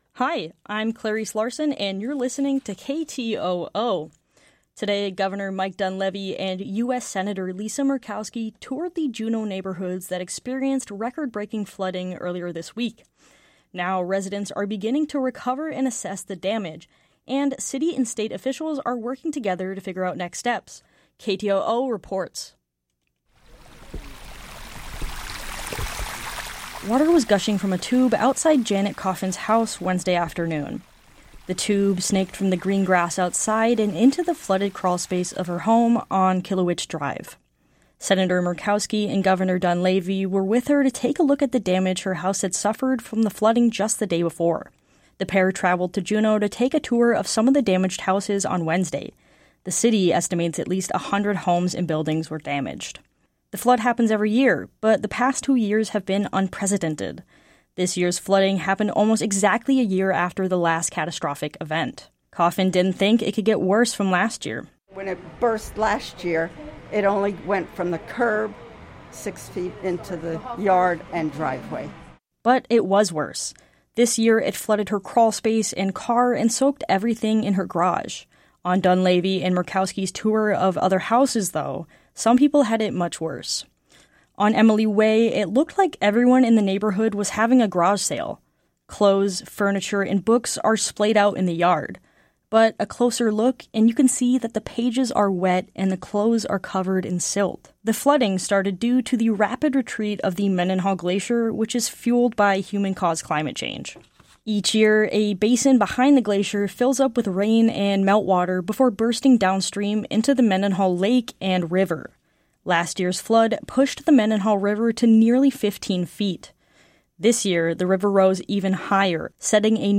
Newscast – Wednesday, Aug. 7, 2024